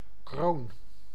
Ääntäminen
France (Paris) : haut: IPA: /o/